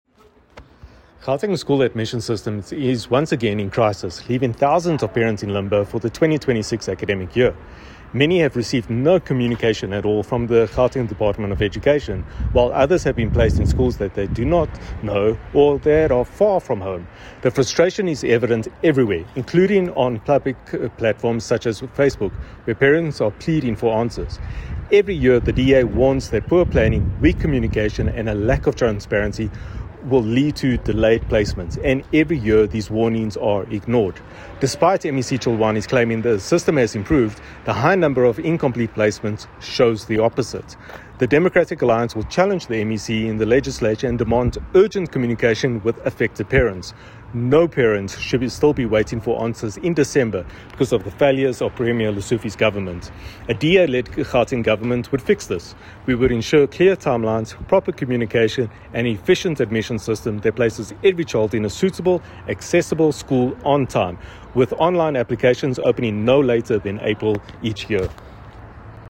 soundbite by Sergio Isa Dos Santos MPL.